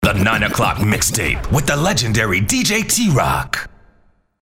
Strong, Versatile, African American, Urban, Caucasian, Caribbean, New York. Professional, Smooth.
mid-atlantic
Sprechprobe: Industrie (Muttersprache):